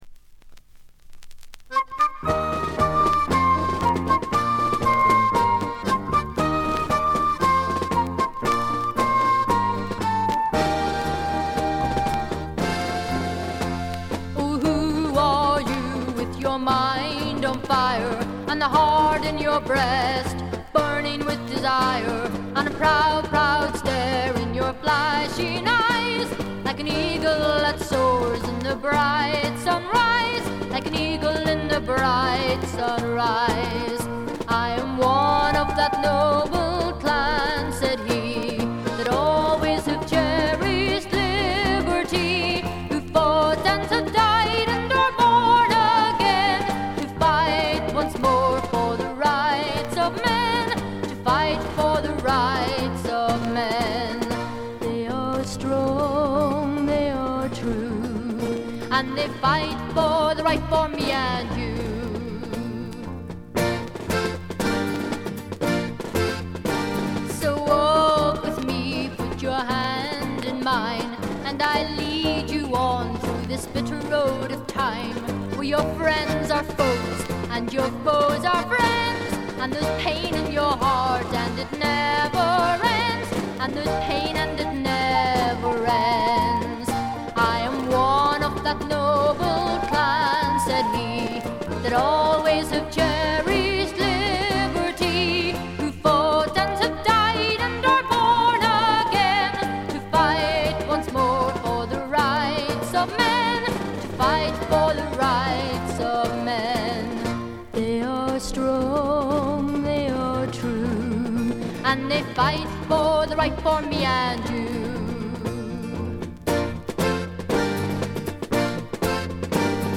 バックグラウンドノイズ、チリプチ、プツ音等多め大きめ。ところどころで周回ノイズもあり。
あとは鼓笛隊的な音が好きなマニアかな？？
試聴曲は現品からの取り込み音源です。
Recorded At - Trend International